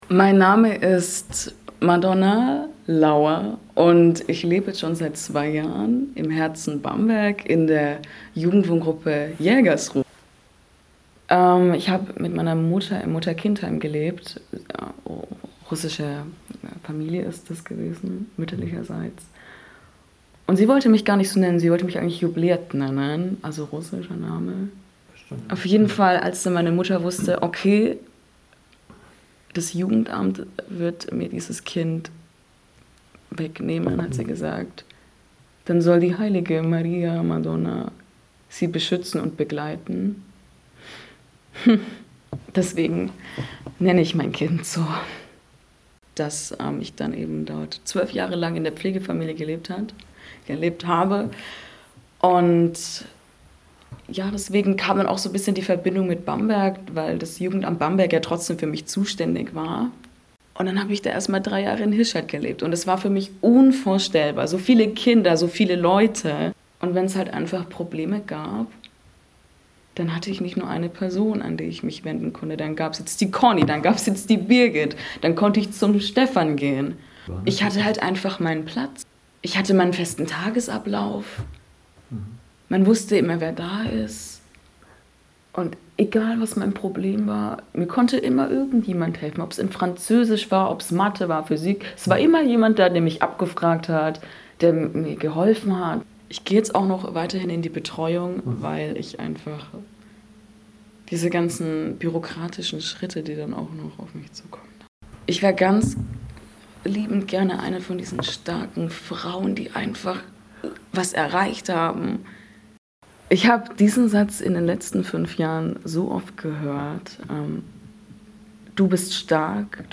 Neben den großformatigen Fotografien können die Ausstellungsbesucher Mitschnitte aus Interviews mit den Porträtierten per QR-Code auf ihrem Smartphone anhören.